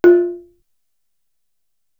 Index of /90_sSampleCDs/Best Service ProSamples vol.55 - Retro Sampler [AKAI] 1CD/Partition D/GAMELAN